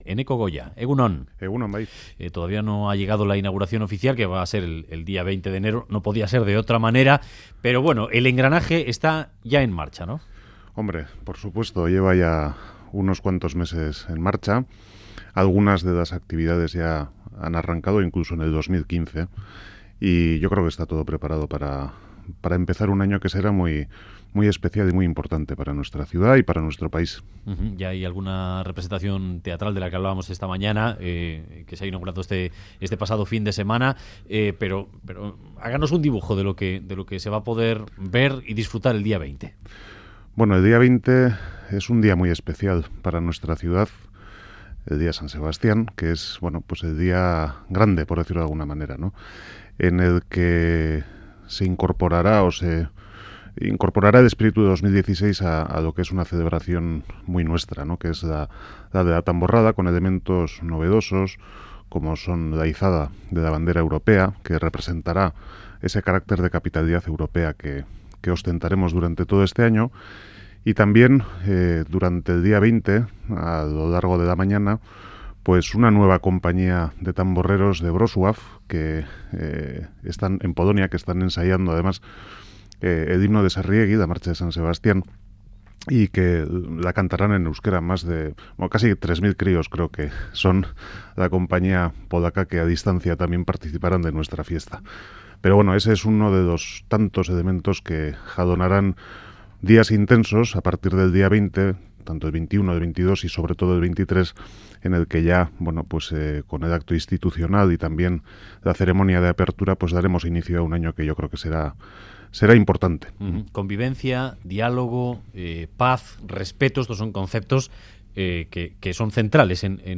Radio Euskadi BOULEVARD Goia: '2016 es para Donostia la oportunidad de una ciudad de éxito' Última actualización: 04/01/2016 10:17 (UTC+1) En entrevista al Boulevard de Radio Euskadi, el alcalde de Donostia, Eneko Goia, ha valorado positivamente la inminente apertura, el próximo 20 de Enero, de la capitalidad cultural Donostia 2016, que considera que es una oportunidad para ser una ciudad de éxito. Ha dicho que va a ser un año especial para San Sebastián y para Euskadi, y ha confiado en que el proyecto de cultura y convivencia que es Donostia 2016 quede ligado a la imagen de nuestro país.